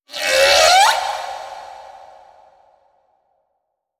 khloCritter_Female16-Verb.wav